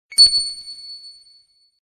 Стандартное SMS на Samsung Wave